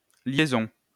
wymowa:
IPA/ljɛ.zɔ̃/ ?/i